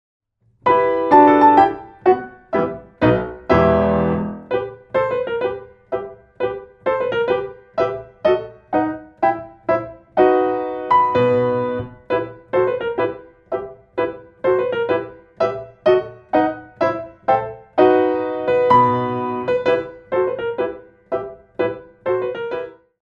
2 bar intro 4/4
32 bars